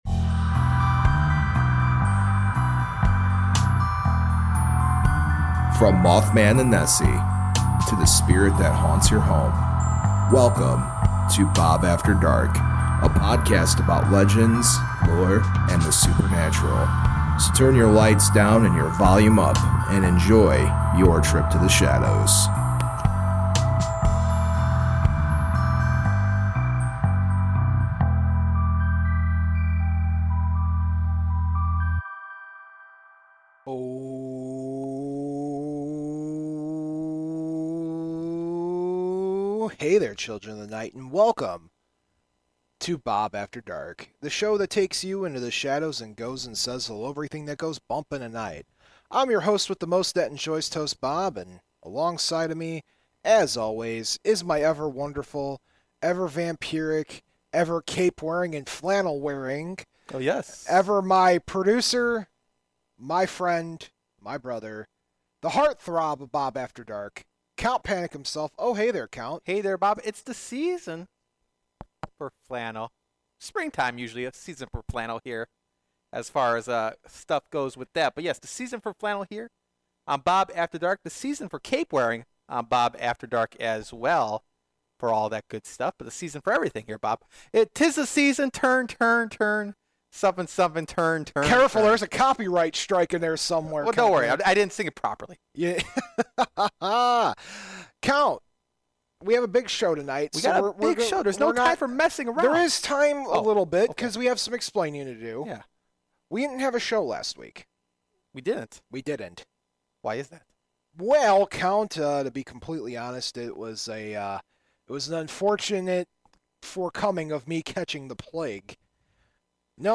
Instead of the run of the mill supernatural investigation show, they go in and try to calm a haunting and try to figure out what is there. There was a lot of laughter, learning about spiritual healing methods known as Reiki, and shared some origin stories.